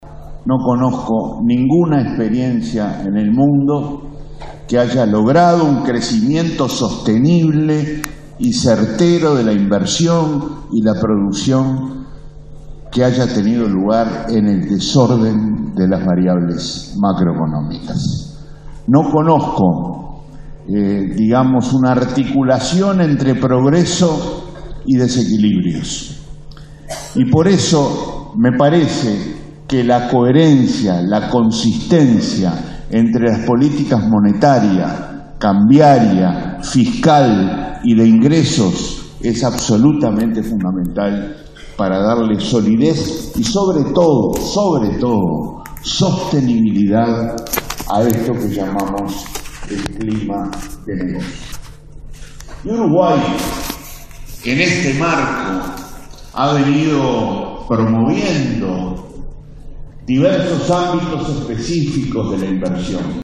El ministro de Economía, Danilo Astori, destacó que en los últimos años Uruguay duplicó la tasa de inversiones y mostró atracción de inversiones extranjeras. En el I Foro de Inversión Europea en Uruguay, dijo que para que esto ocurra debe existir transformación institucional, estímulos y orden macroeconómico.